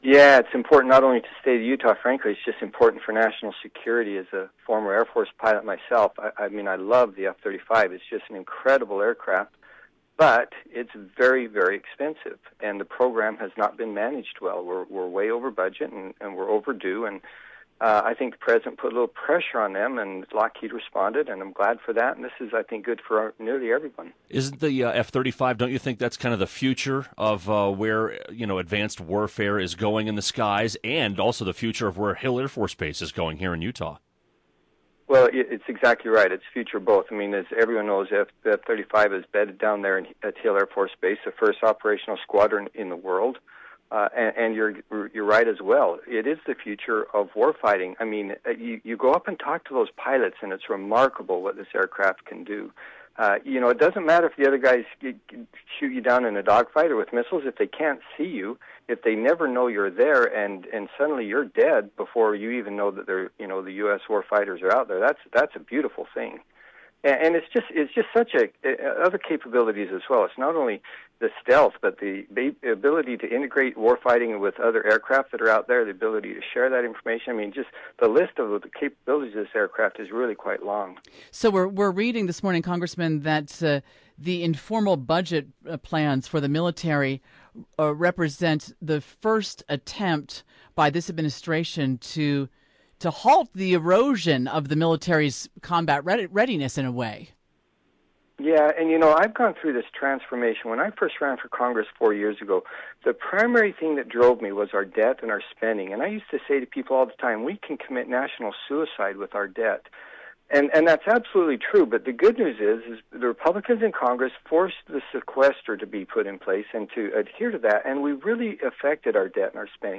Republican Congressman Chris Stewart joins